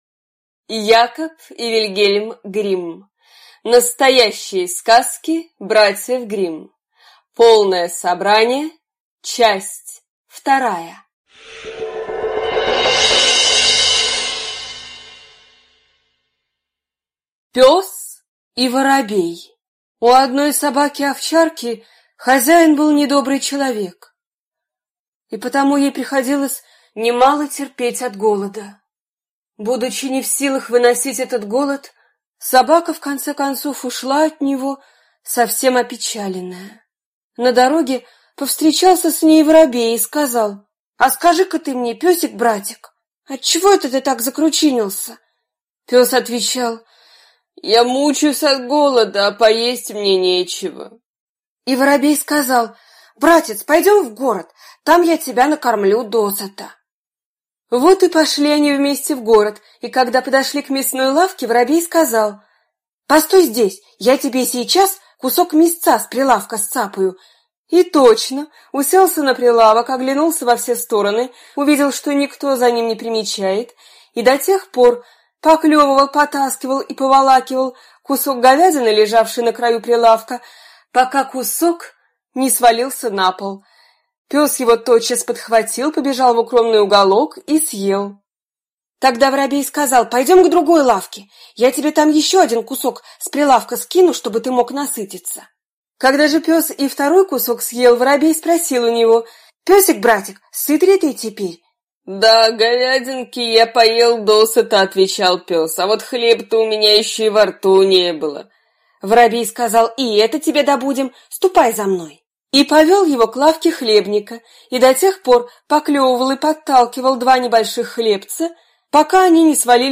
Аудиокнига Настоящие сказки братьев Гримм. Часть 2 | Библиотека аудиокниг